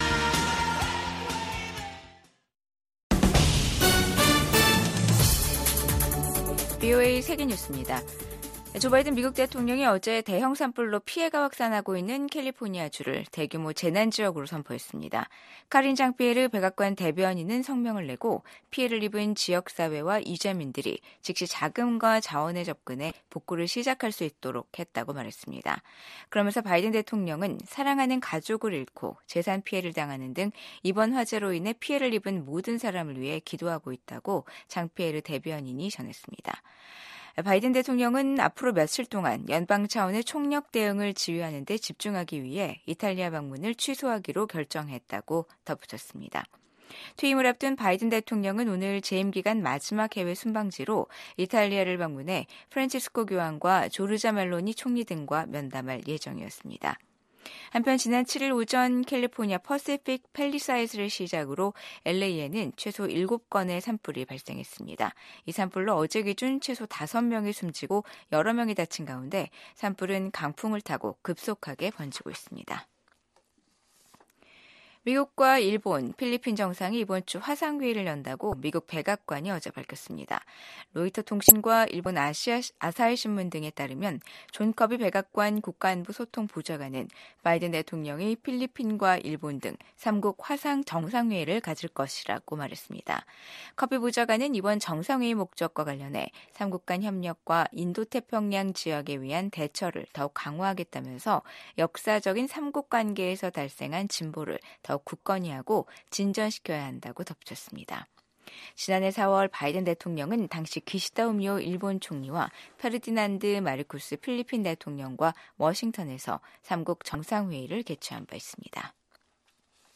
VOA 한국어 간판 뉴스 프로그램 '뉴스 투데이', 2025년 1월 9일 3부 방송입니다. 미국 국방부는 최근 북한의 탄도미사일 발사를 인지하고 있으며 동맹과 긴밀히 협력하고 있다고 밝혔습니다.